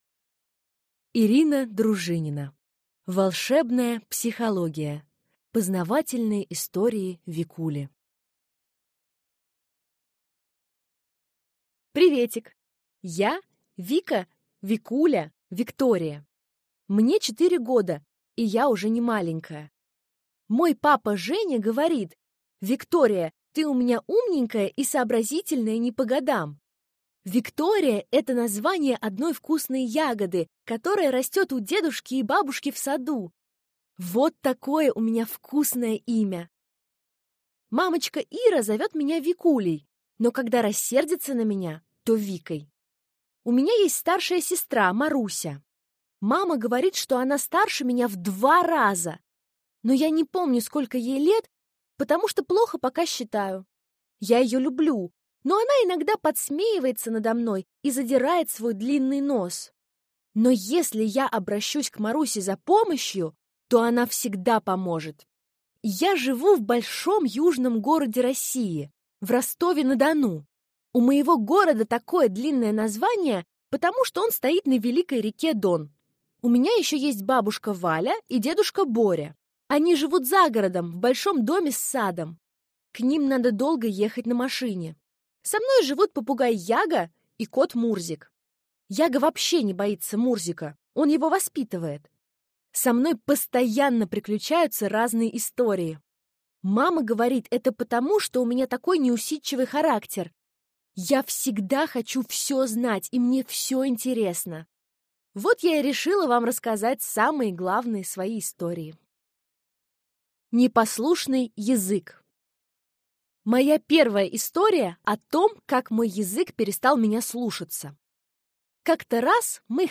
Аудиокнига Волшебная психология. Познавательные истории Викули | Библиотека аудиокниг